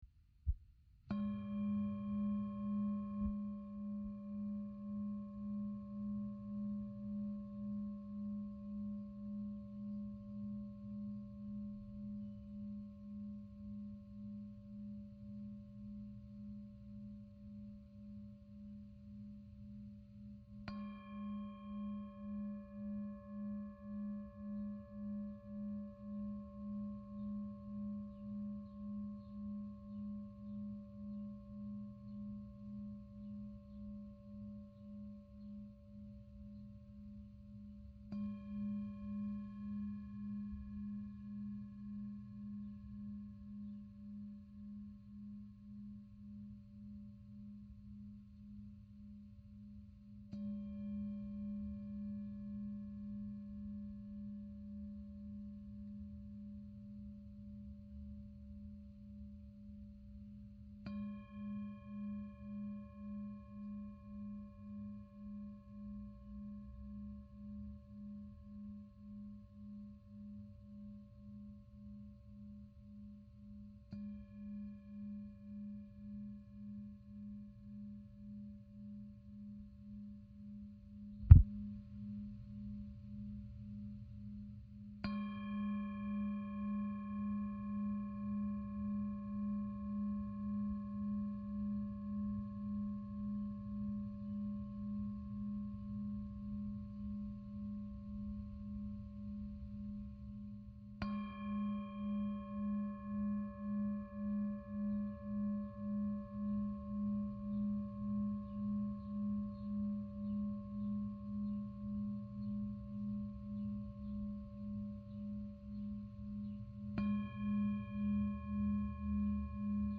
Gong Meditation
Simply place your attention on to the sound of the gong. And when your mind wanders, which it will, let the next strike of the gong bring your attention back to your senses and away from your thoughts.
meditation-gong.mp3